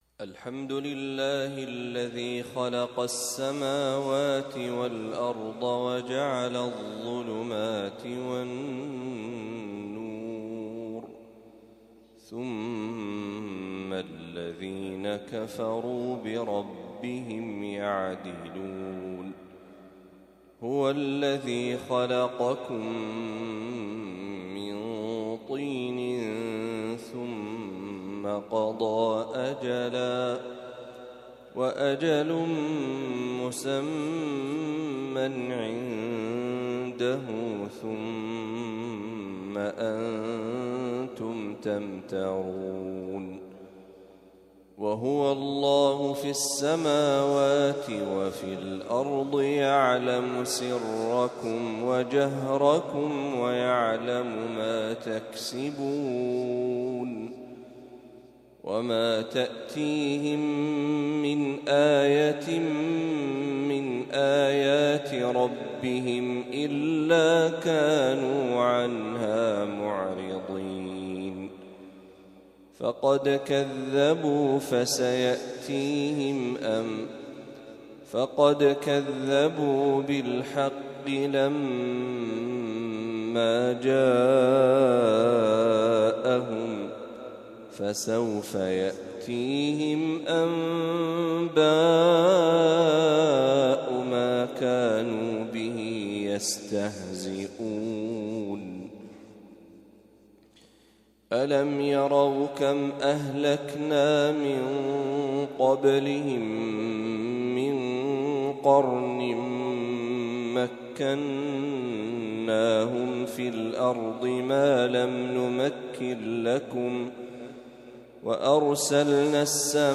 فواتح سورة الأنعام | فجر الأحد ٥ ربيع الأول ١٤٤٦هـ > 1446هـ > تلاوات الشيخ محمد برهجي > المزيد - تلاوات الحرمين